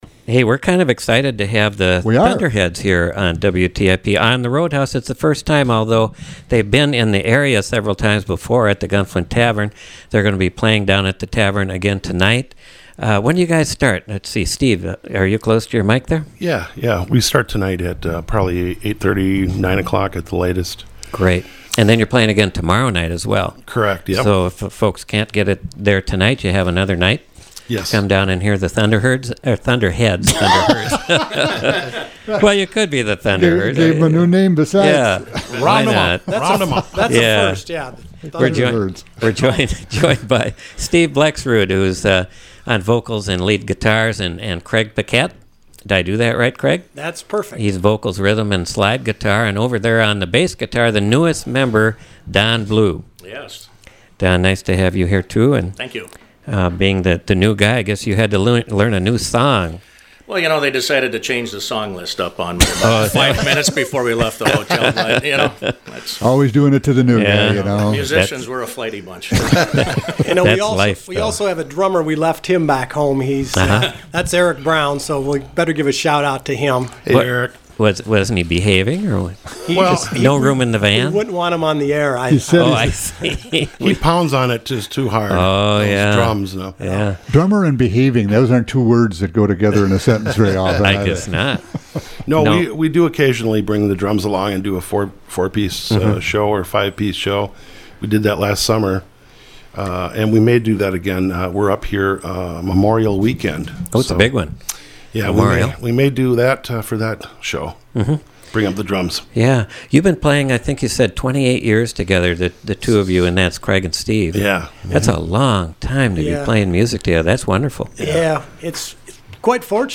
Live Music Archive